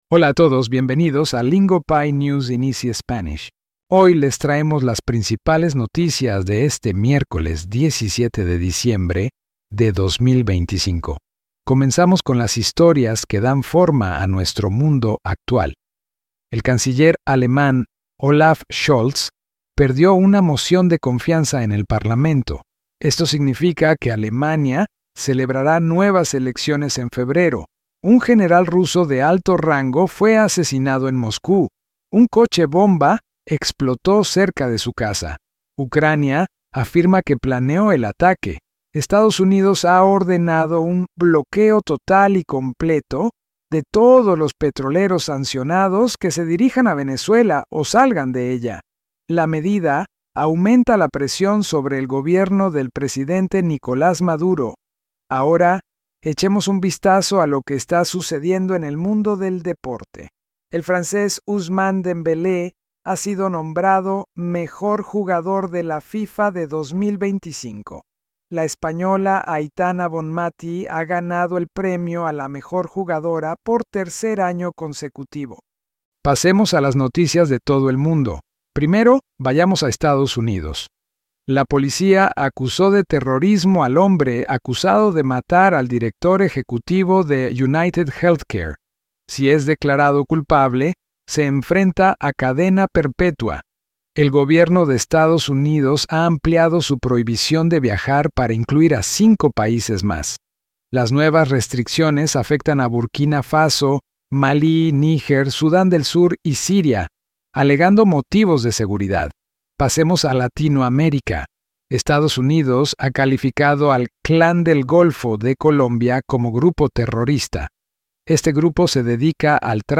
Lingopie's News in Easy Spanish actually gives you something worth listening to, in clear and natural Spanish built for learners.